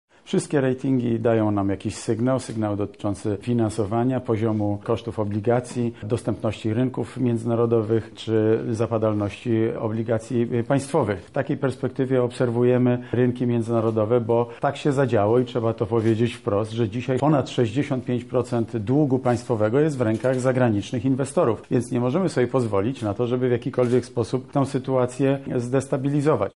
Mateusz Morawiecki spotkał się dzisiaj ze studentami na Katolickim Uniwersytecie Lubelskim.
Mówi wicepremier Mateusz Morawiecki